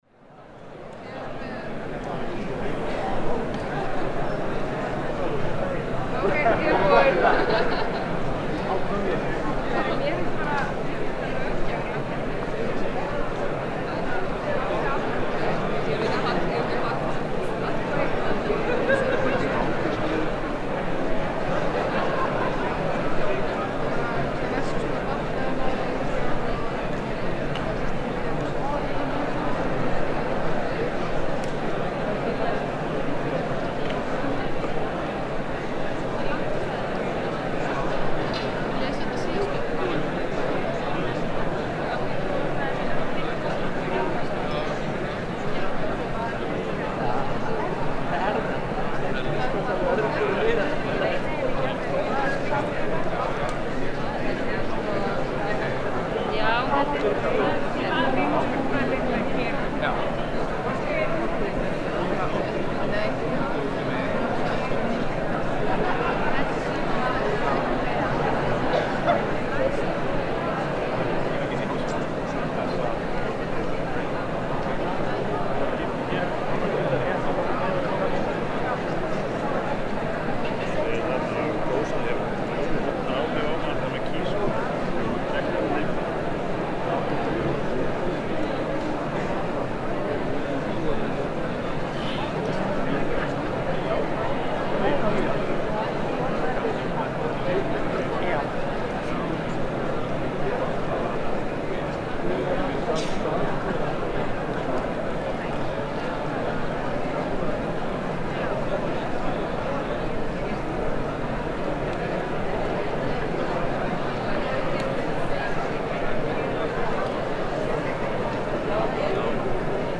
Efnt var til Þjóðfundar 14. nóvember 2009 í Laugardalshöll. Í heilan dag vann mikill fjöldi fólks að því að reyna að bæta íslenskt samfélag með skapandi hugmyndavinnu.
Upptakan fór fram með þeim hætti að genginn var einn hringur um salinn með Rode NT4 hljóðnema.